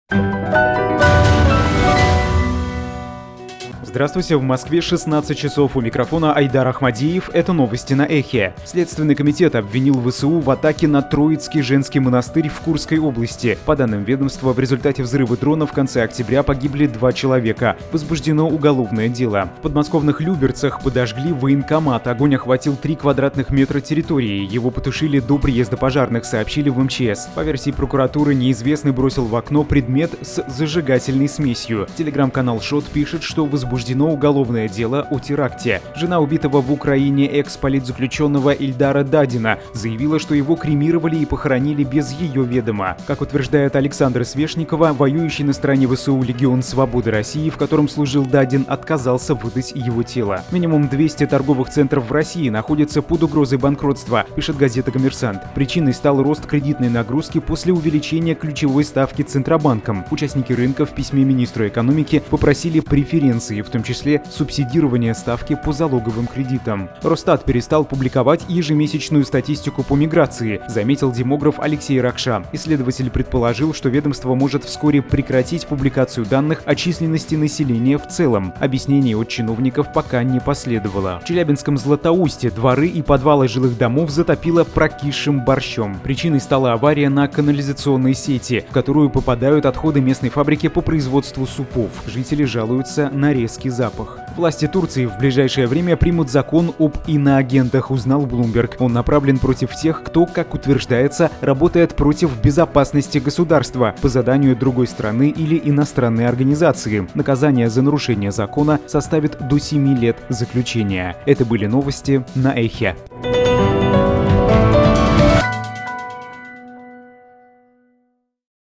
Слушайте свежий выпуск новостей «Эха».
Новости